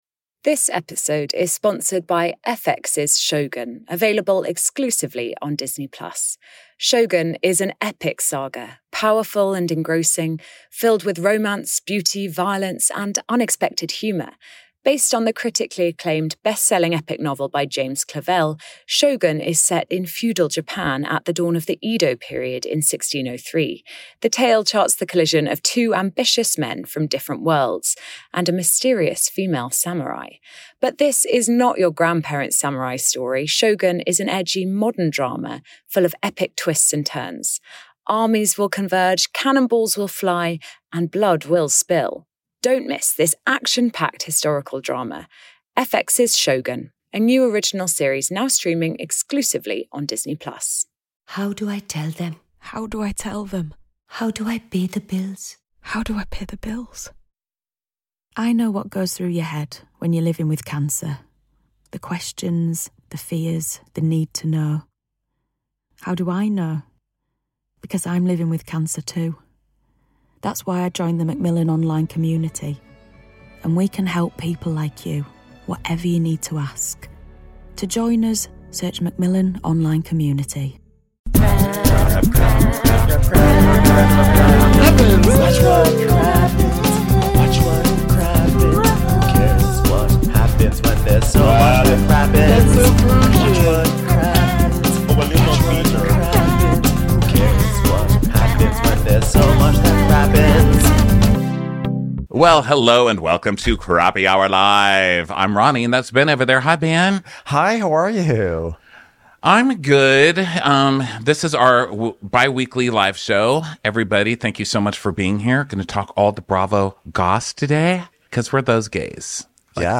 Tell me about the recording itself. #2347 Crappy Hour Live 3/4/24: Bravo Docket, Rachel V Tom and Ariana, Beer Cheese Trouble